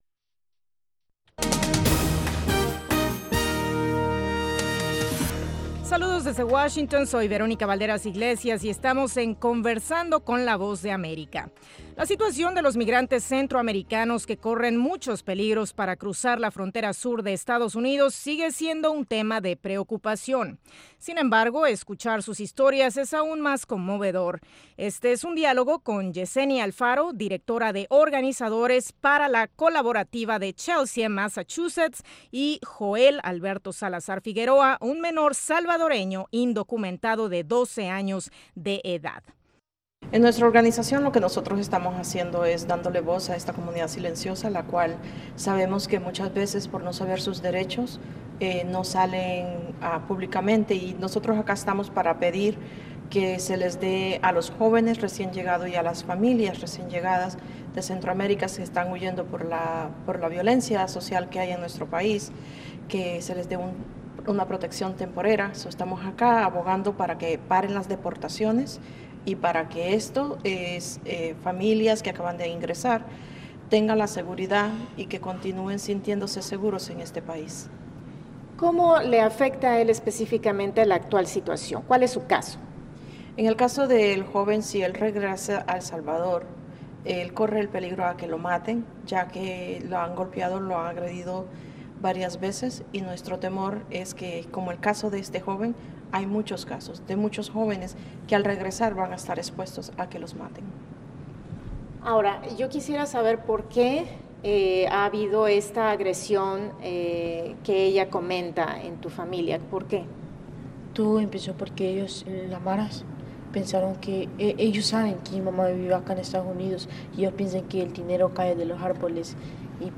Entrevista VOA - 12:30pm
La Voz de América entrevista, en cinco minutos, a expertos en diversos temas.